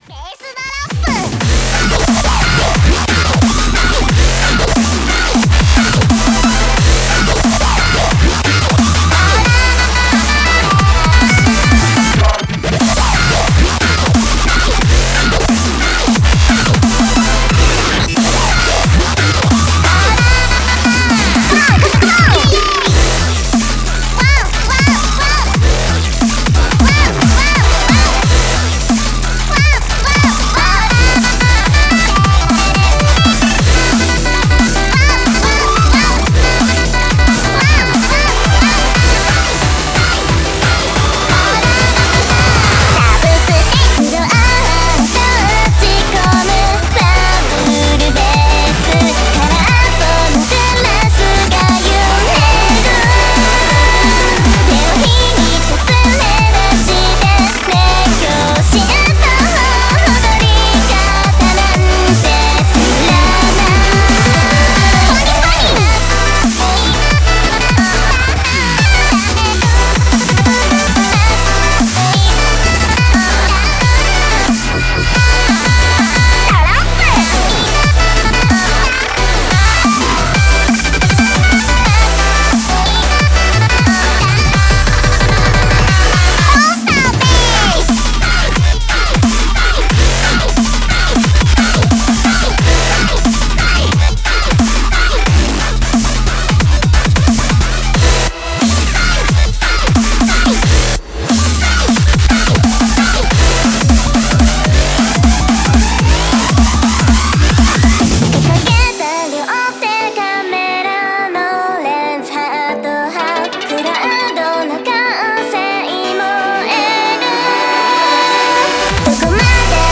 applause.wav